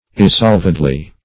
Search Result for " irresolvedly" : The Collaborative International Dictionary of English v.0.48: Irresolvedly \Ir`re*solv"ed*ly\, adv.